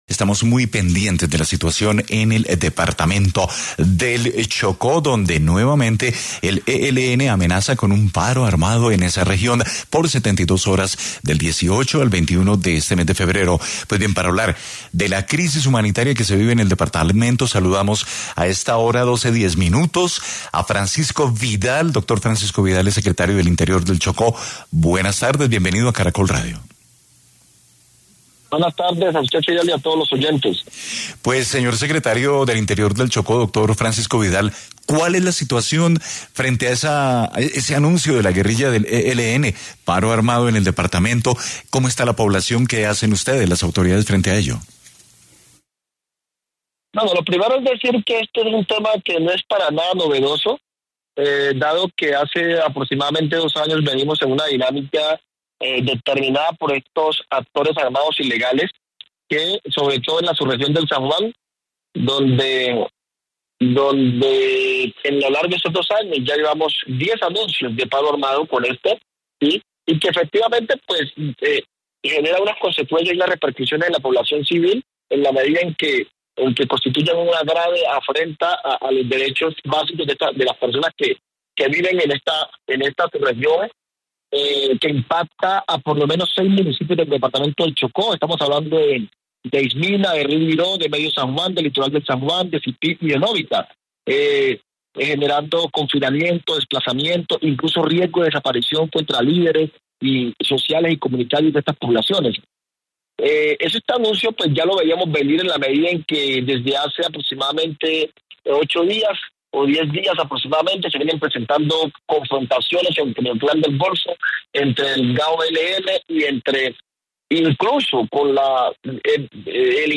Así lo reveló en Caracol Radio Francisco Vidal, secretario de Interior de la Gobernación del Chocó
En medio de un anuncio de paro armado del ELN en el departamento del Chocó que dice será por 72 horas del 18 al 21 de febrero y una crisis humanitaria que viven las comunidades, Francisco Vidal, el secretario del Interior del Chocó, atendió los micrófonos de Caracol Radio para hablar de esta situación.